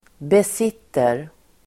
Ladda ner uttalet
Uttal: [bes'it:er]